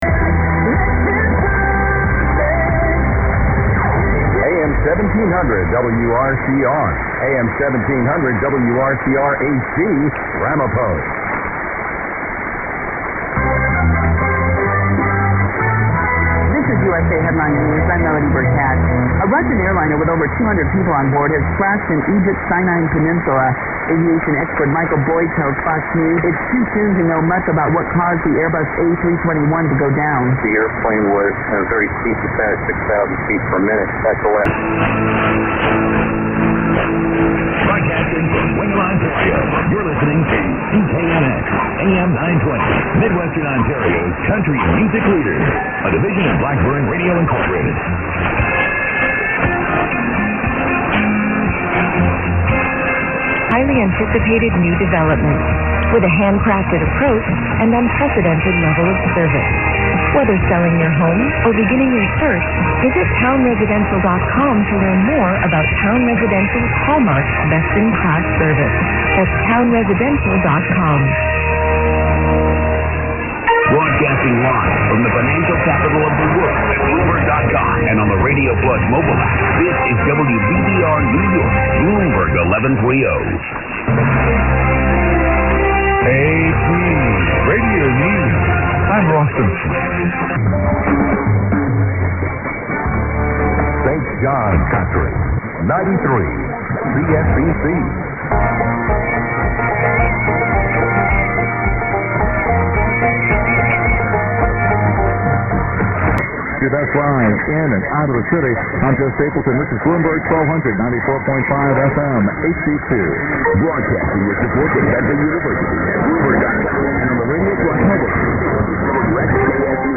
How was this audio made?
I have also started a montage of various stations but not quite finished.